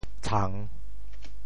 “灿”字用潮州话怎么说？